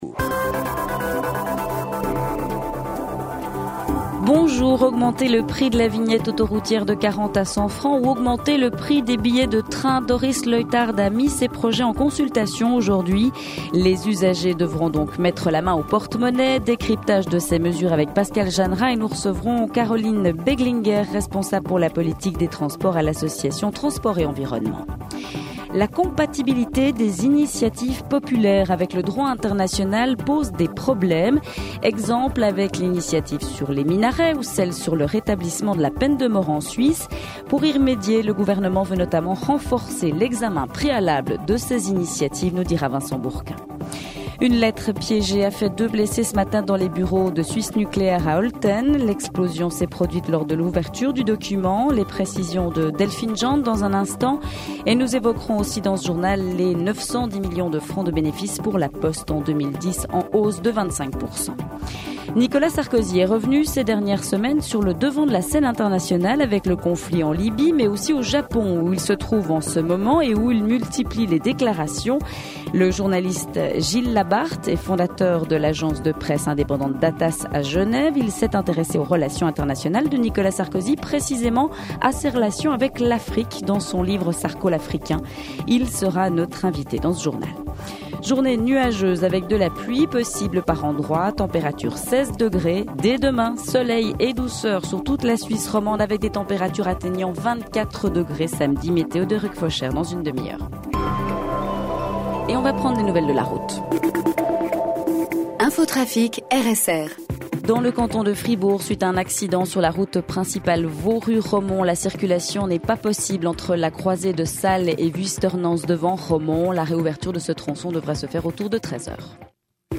Le 12h30, c’est le grand rendez-vous d’information de la mi-journée. L’actualité dominante y est traitée, en privilégiant la forme du reportage/témoignage pour illustrer les sujets forts du moment.